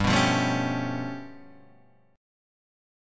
G9b5 chord